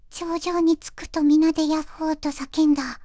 voicevox-voice-corpus / ita-corpus /中国うさぎ_こわがり /EMOTION100_016.wav